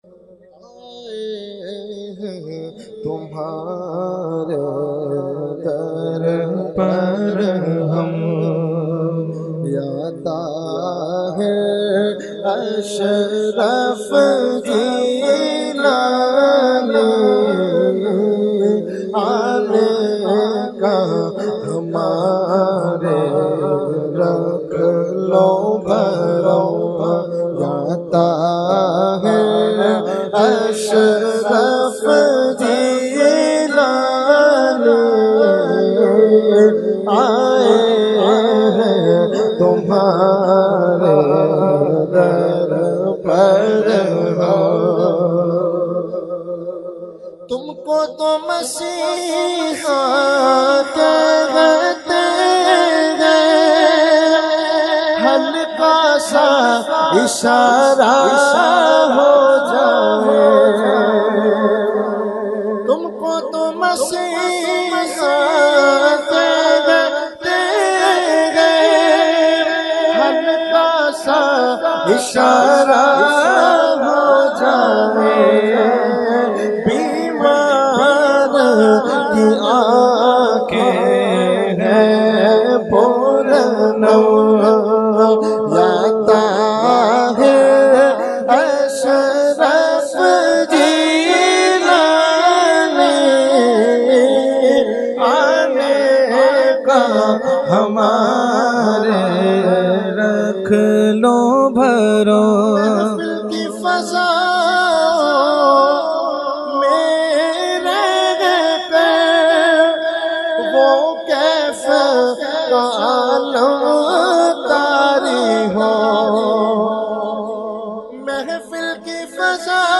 held on 1,2,3 January 2021 at Dargah Alia Ashrafia Ashrafabad Firdous Colony Gulbahar Karachi.
Category : Manqabat | Language : UrduEvent : Urs Qutbe Rabbani 2021